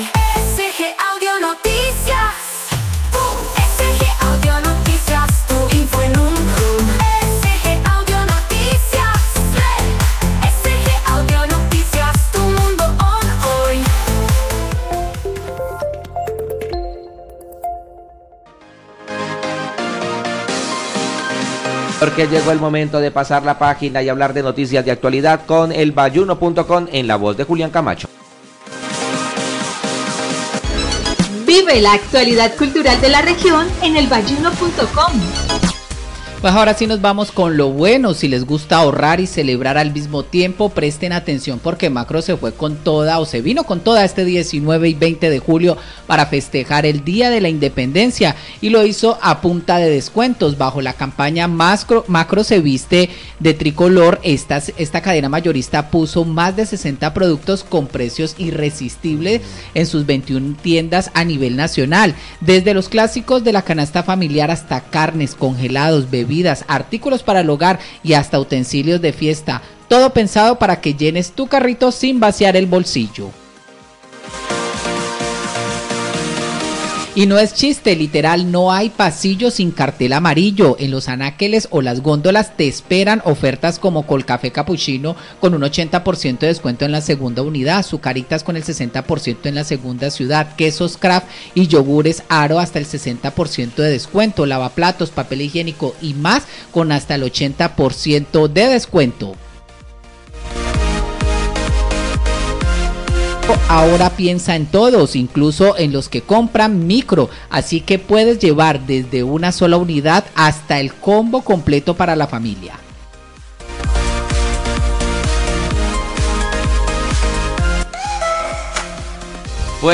SG-AudioNoticia-Makro.mp3